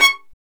Index of /90_sSampleCDs/Roland - String Master Series/STR_Violin 1-3vb/STR_Vln2 % + dyn
STR VLN JE1J.wav